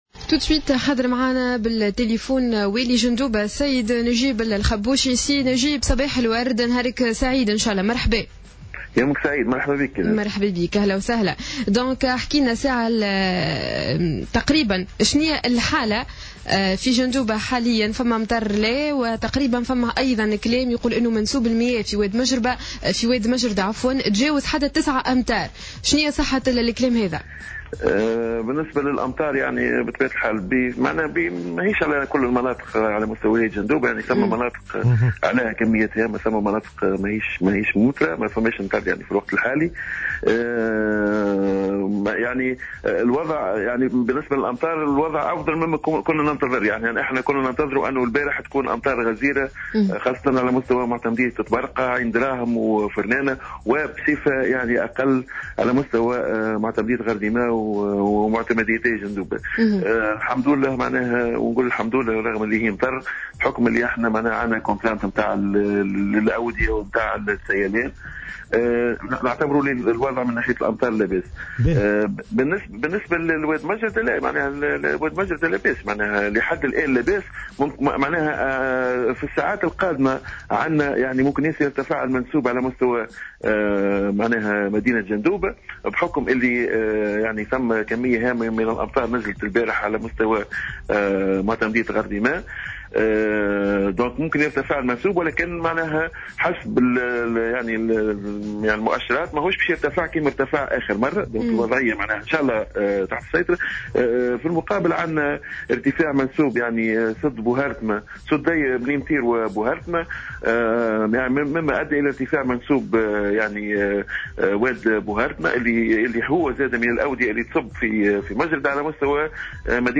أكد نجيب الخبوشي والي جندوبة في تصريح لجوهرة أف أم أن ارتفاع منسوب وادي مجردة بسبب الأمطار الأخيرة لن يكون كبيرا ولن يتسبب في فيضان الوادي معتبرا أن الوضعية "تحت السيطرة"، لكن في المقابل شهد وادي بوهرتمة من معتمدية بوسالم ارتفاعا في منسوب المياه وهو أحد الأودية التي تصب في وادي مجردة معتبرا أن ذلك لن يتسبب في فيضان الوادي على مستوى ولاية جندوبة.